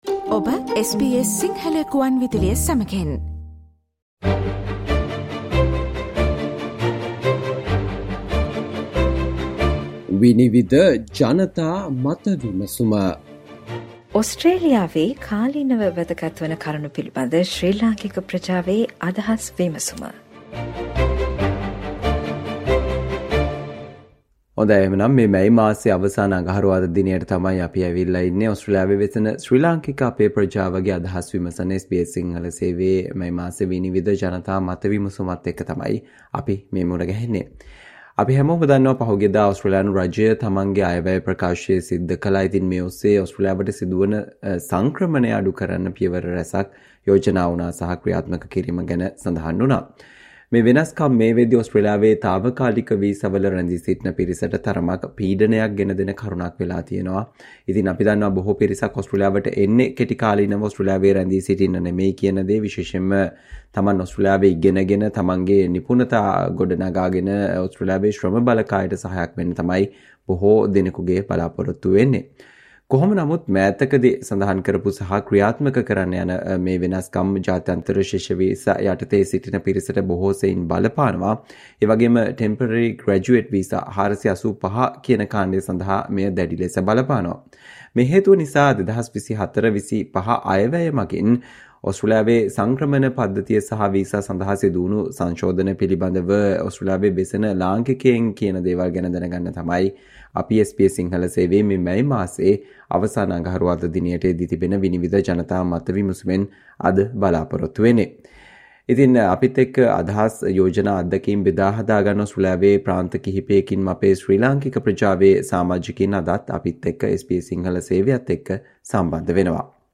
Listen to ideas of International students and those on 485 visas talking about, their experiences on the amendments to Australia's immigration system and visas introduced in the 2024-25 Budget
Panel discussion